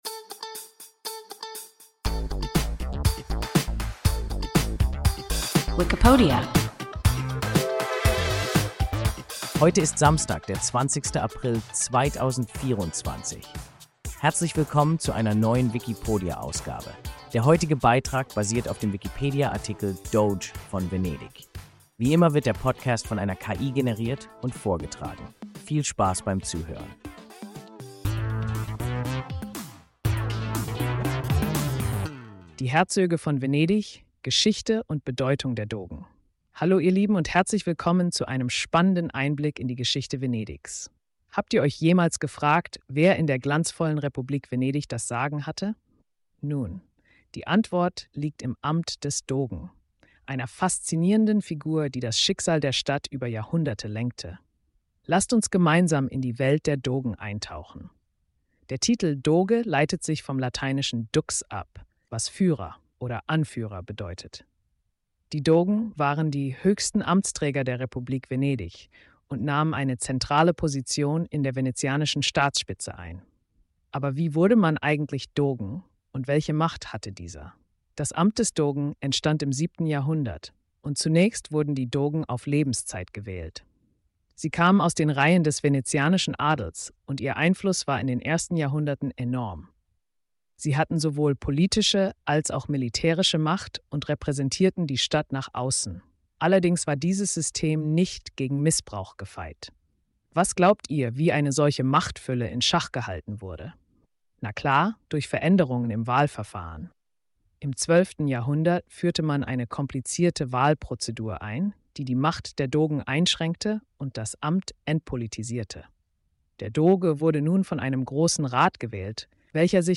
Doge von Venedig – WIKIPODIA – ein KI Podcast